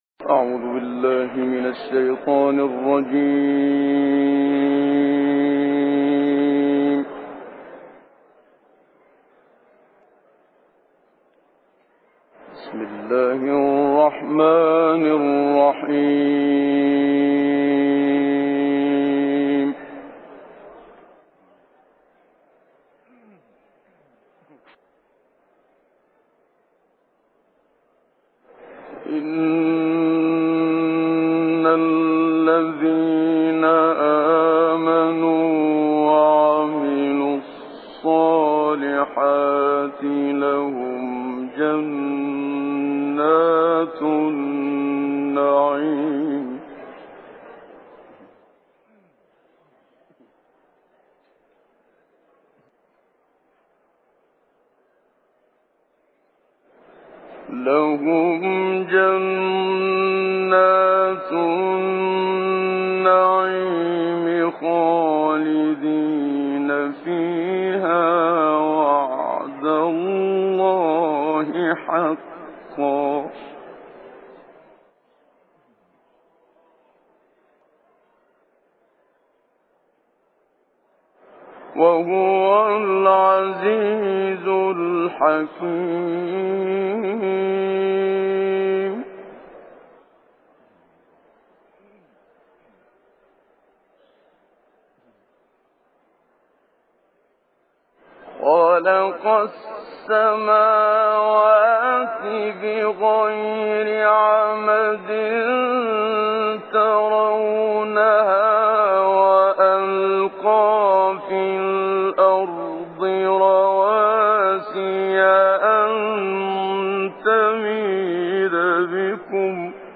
تلاوت سوره لقمان با صوت قاریان برجسته مصری
گروه شبکه اجتماعی: تلاوت آیات زیبایی از سوره مبارکه لقمان با صوت برخی از قاریان برجسته مصری را می‌شنوید.